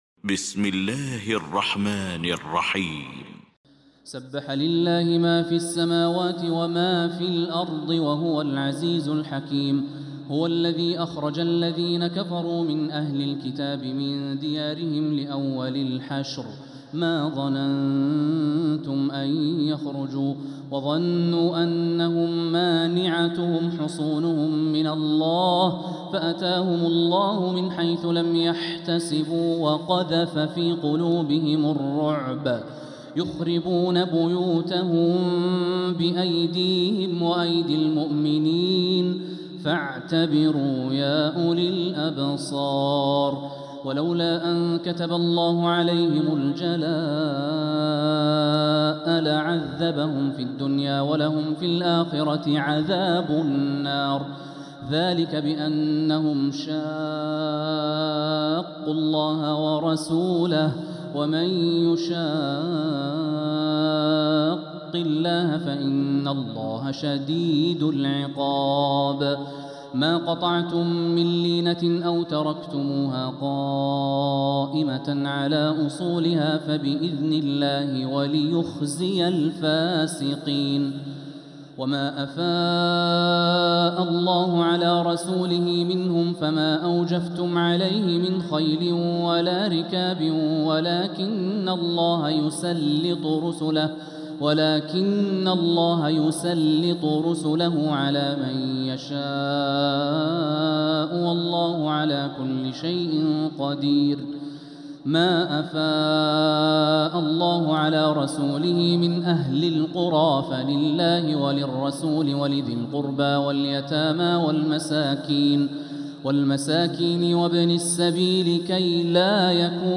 المكان: المسجد الحرام الشيخ: فضيلة الشيخ د. الوليد الشمسان فضيلة الشيخ د. الوليد الشمسان الحشر